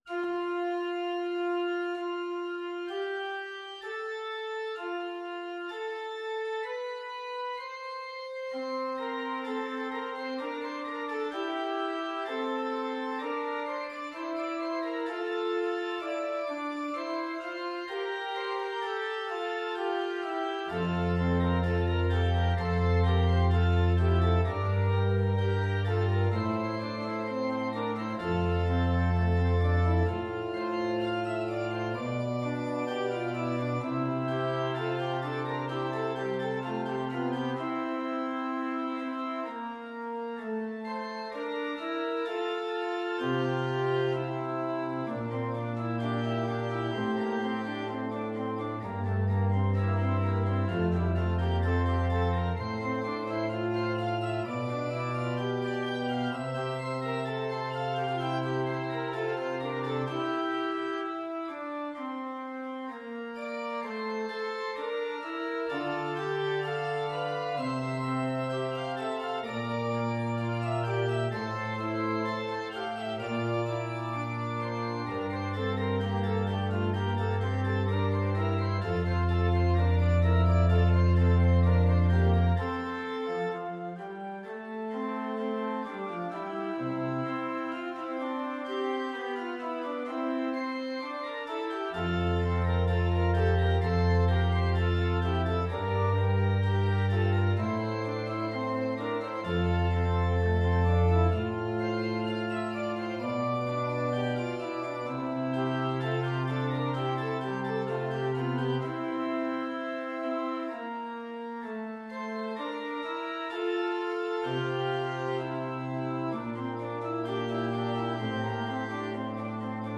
Free Sheet music for Organ
Organ  (View more Intermediate Organ Music)
Classical (View more Classical Organ Music)